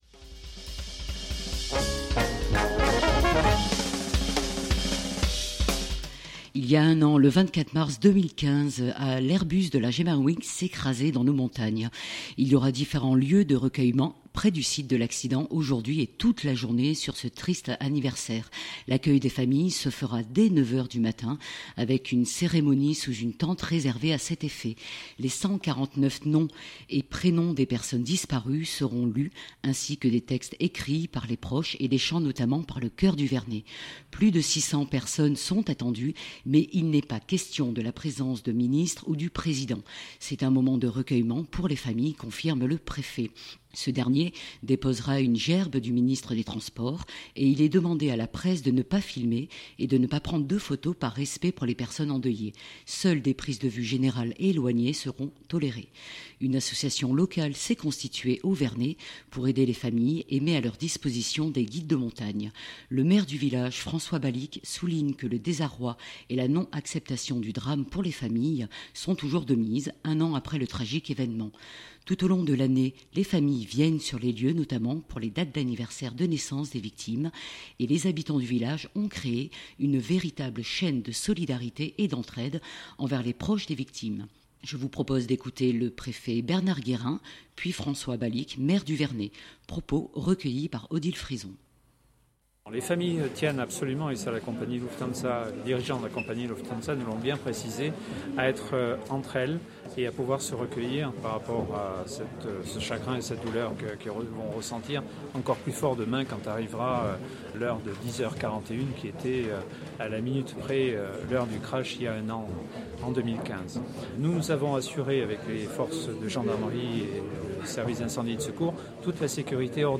Je vous propose d’écouter le préfet Bernard Guérin, puis François Balique, maire du Vernet.